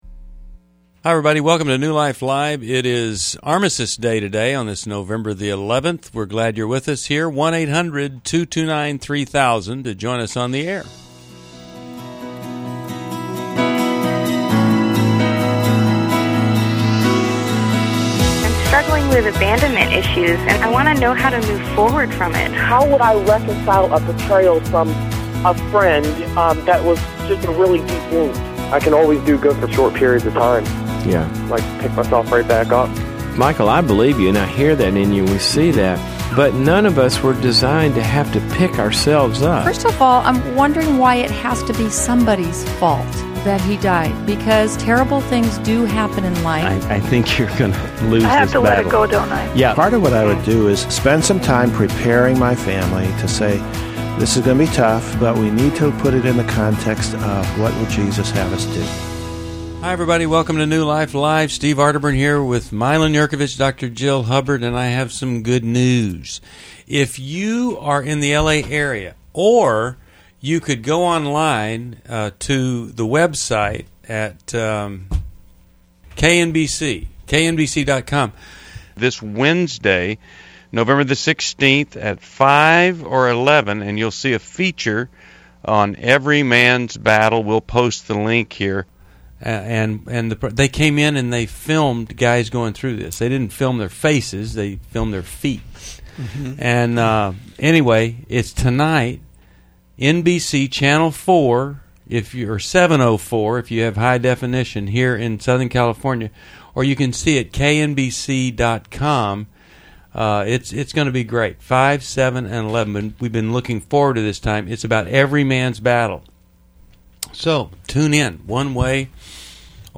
New Life Live: November 11, 2011 - Explore fear, anxiety, and parenting issues as callers seek guidance on trust, addiction, and coping strategies.